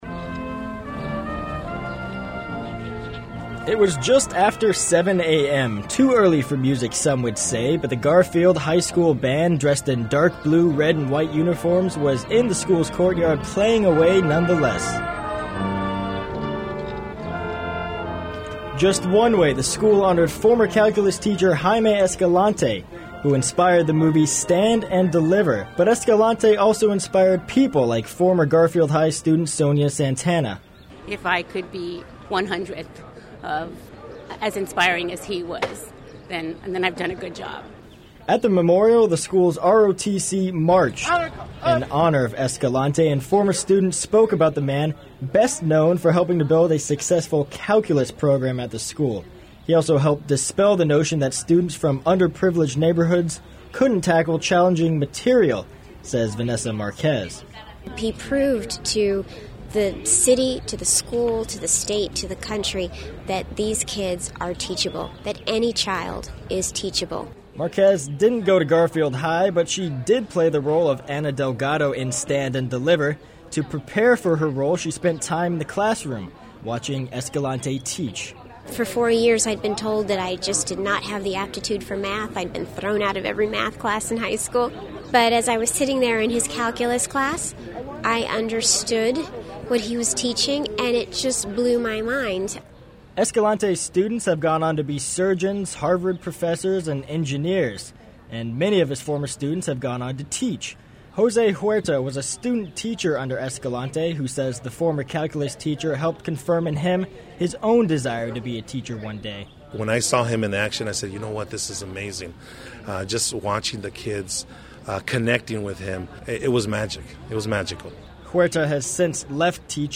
It was just after 7 a.m., too early for music some would say, but the Garfield High school band, dressed in dark blue, red, and white uniforms was in the school courtyard playing away nonetheless.